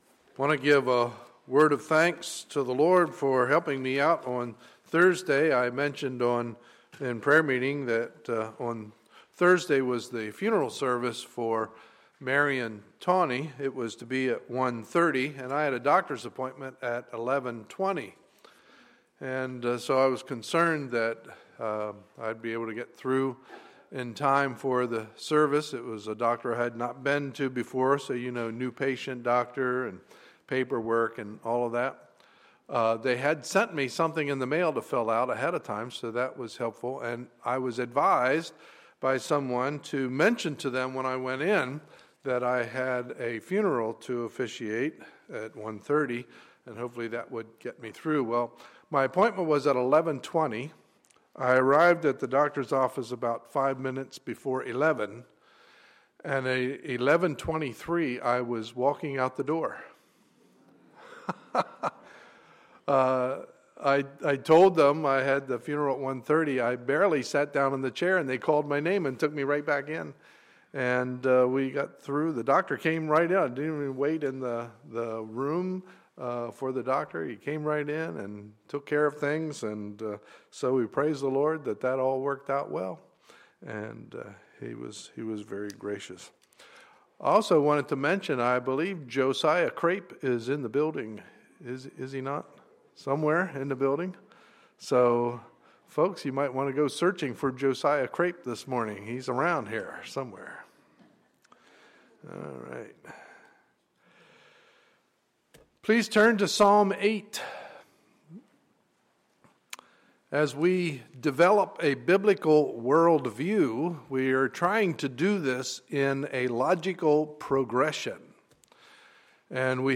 Sunday, February 2, 2014 – Morning Service